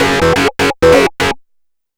RIFFSYNT01-L.wav